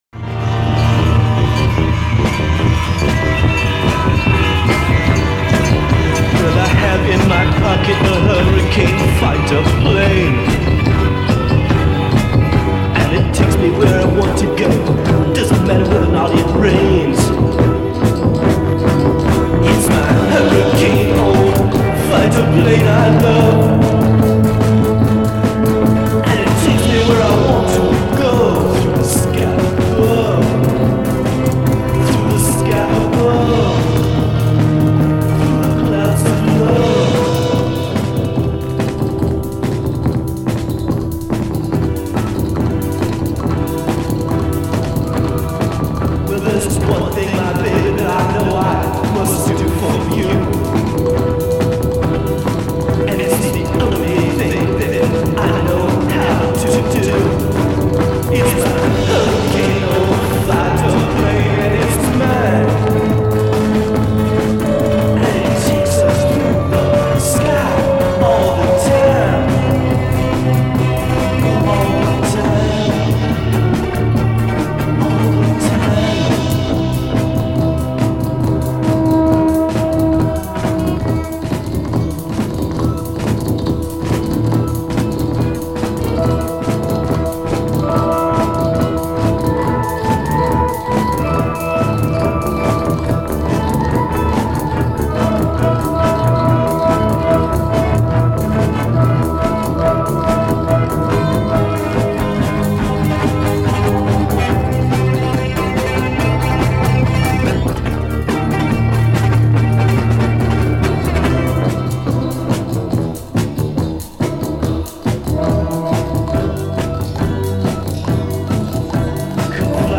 Music took a turn for uncharted territory.
Mind-blowing sounds at their best.